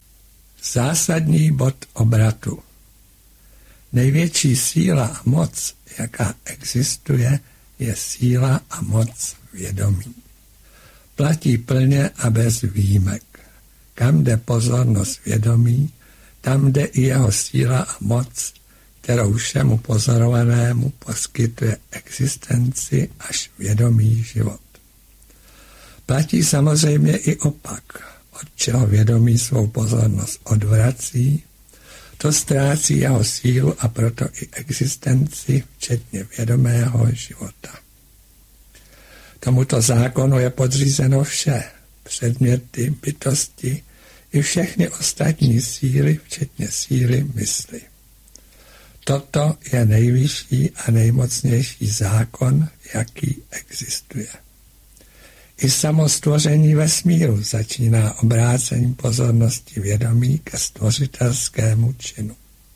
Přímá stezka k poznání sebe - 1.díl. Autor vysvětluje meditační postupy, které vedou bezprostředně k uvědomění si našeho pravého Já, kterým je vědomí Já jsem. Poté pojednává o důležitosti mistra a o správném vztahu k němu. Nejedná se o studiovou nahrávku.